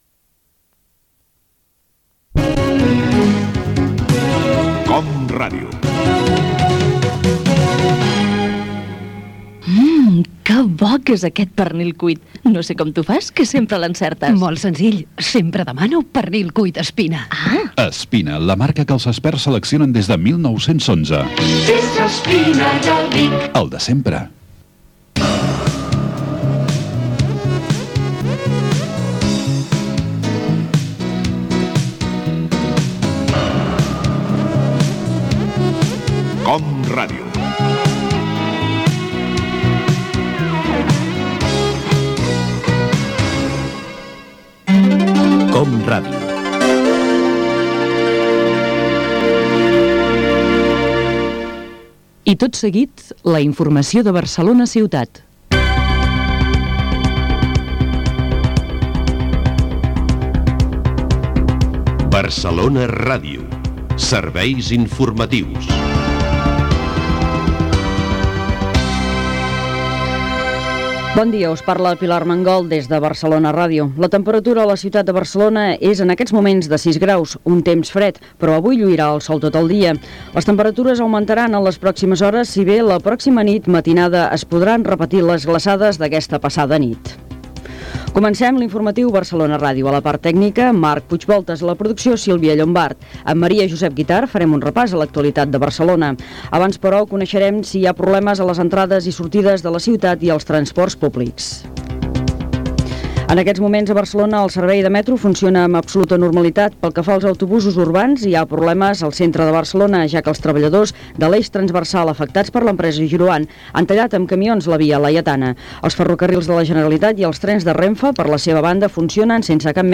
Publicitat, indicatiu de COM Ràdio, careta del programa, hora, el temps, equip, informació del transport públic i del trànsit, indicatiu, hora, l'Ajuntament s'afegeix als actes en protesta per l'assassinat de Tomás y Valiente, acord per construir la pota sud d'accés a Barcelona (declaracions d'Artur Mas i Luis Tejedor), requalificació dels terrenys del camp de futbol de l'Avinguda Sarrià del Real Club Deportivo Español
Informatiu
FM